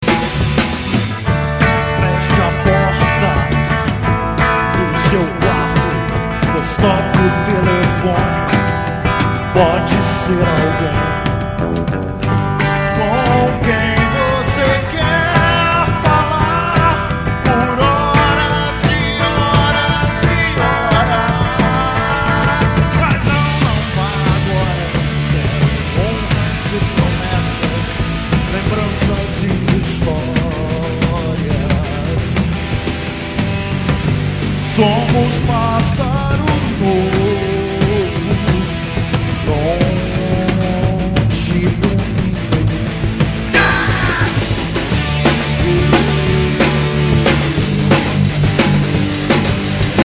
No último domingo, duas gerações do rock jacutinguense estiveram reunidas no Jacka Studio.
fizeram uma jam session.